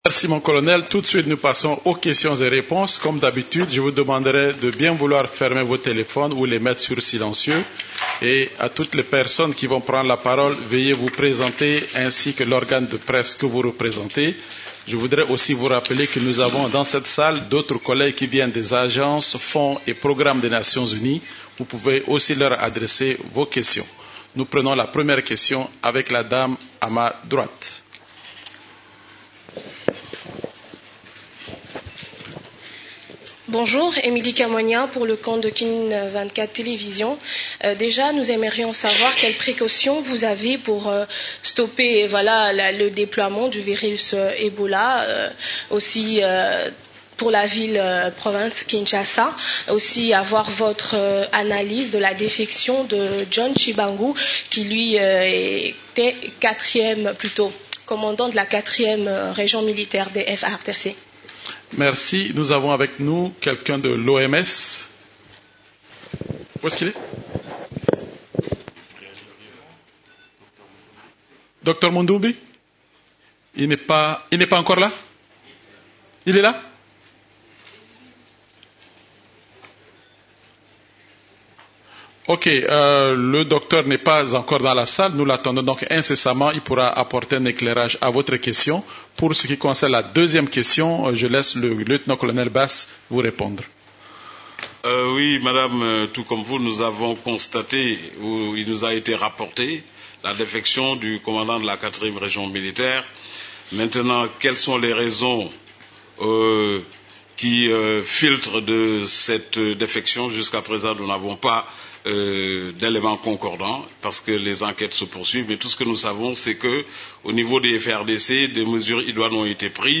La conférence hebdomadaire des Nations unies du mercredi 22 août a porté sur les sujets suivants :